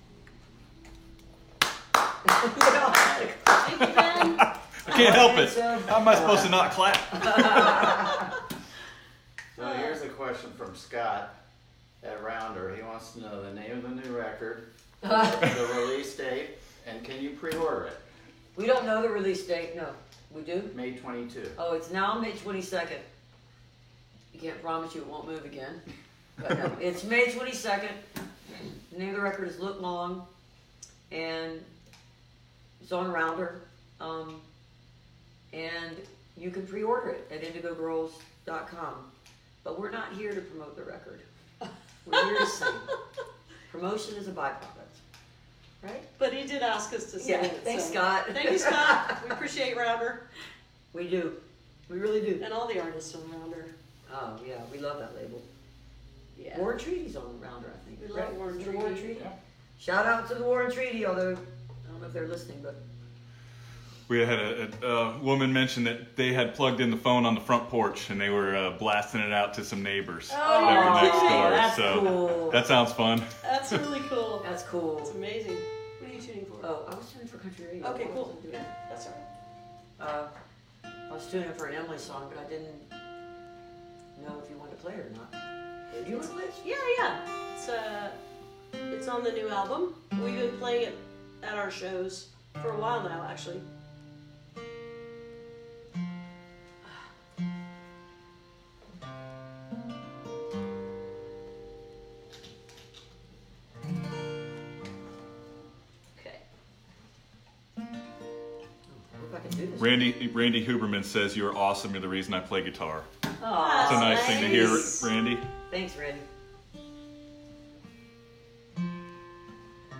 lifeblood: bootlegs: 2020-03-19: facebook live online show
24. talking with the crowd (2:18)